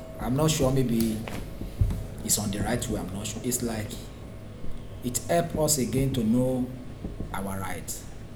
S3 = Nigerian male
Intended Words: help us Heard as: a post Discussion: There is no initial [h] in help .